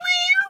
cat_2_meow_03.wav